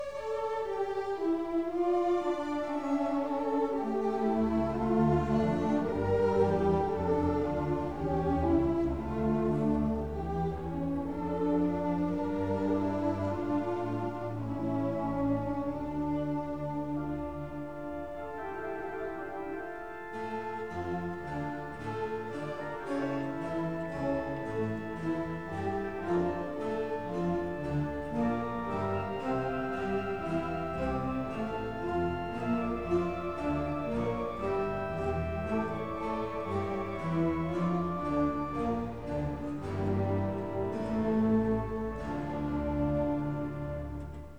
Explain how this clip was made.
1958 stereo recording